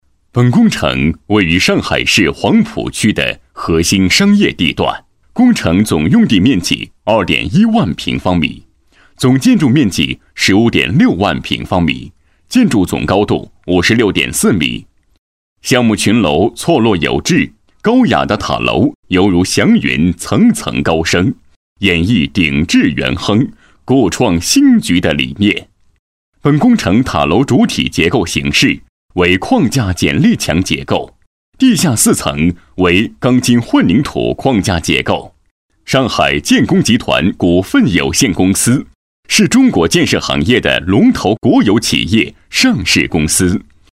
激情力度 规划总结配音
磁性沉稳，声线偏中年。擅长记录片，专题汇报 ，宣传片等题材。